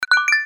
короткие
звонкие